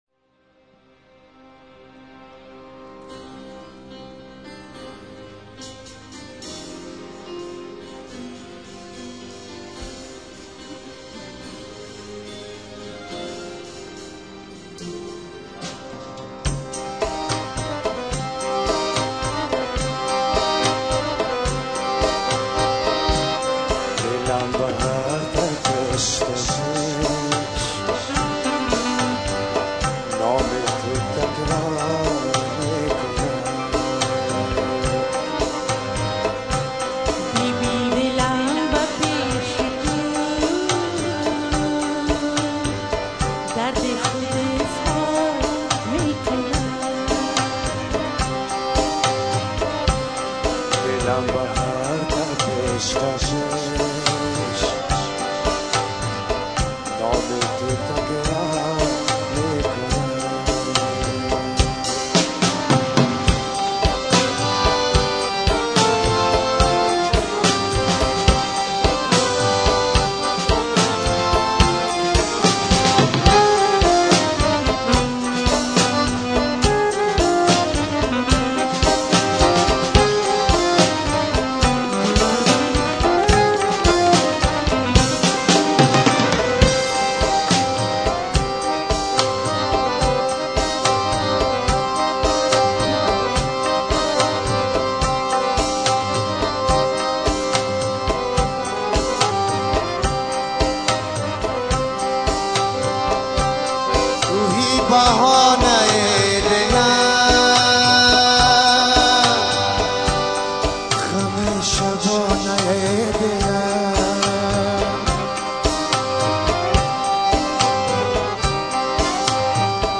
Concert (germany)